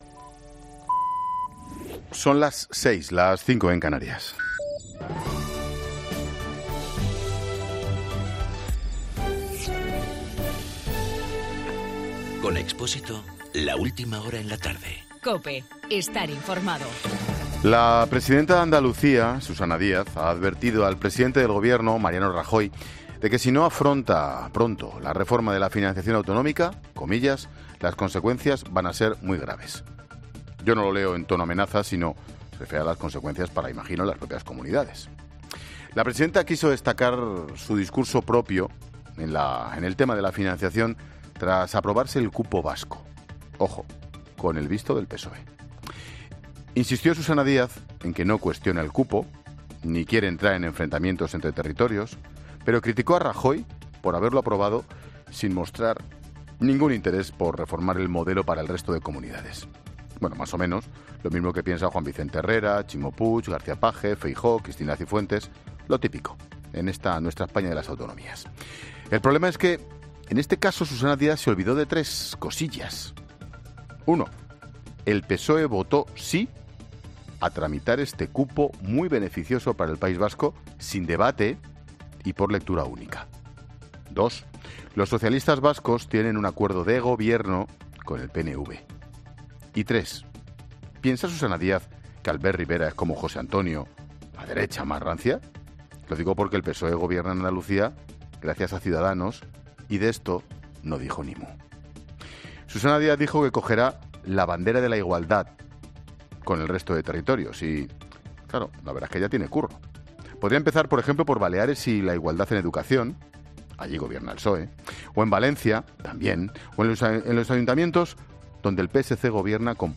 Monólogo de Expósito
Ángel Expósito analiza en su monólogo de las 18 horas la situación del Partido Socialista.